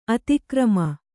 ♪ ati krama